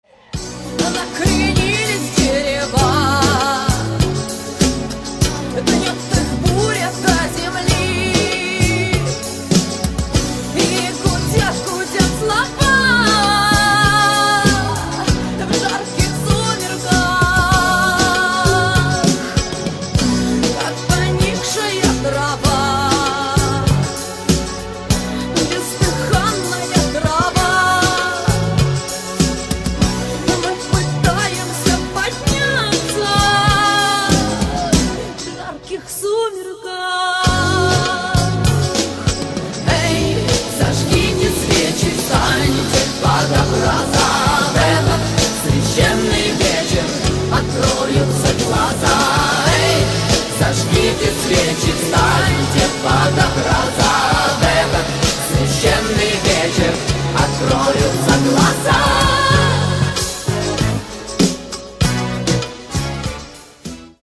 • Качество: 320, Stereo
женский вокал
90-е
русский рок